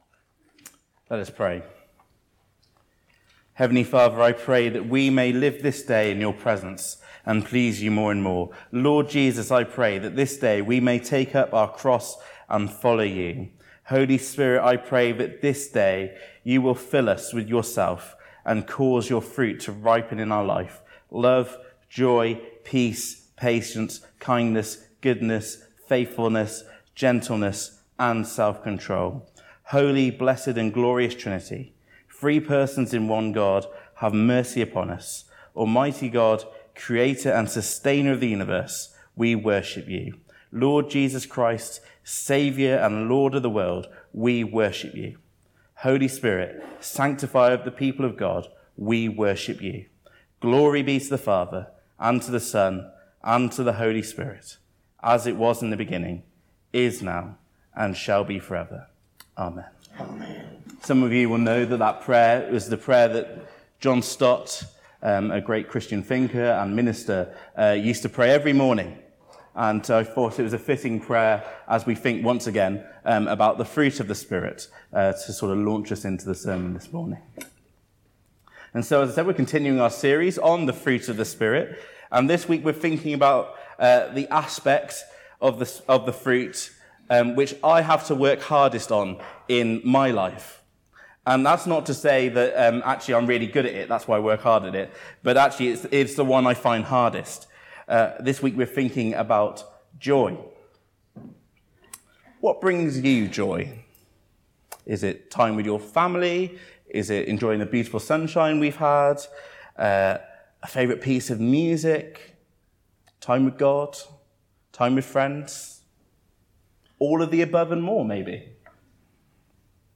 SERMON: Hope in ruins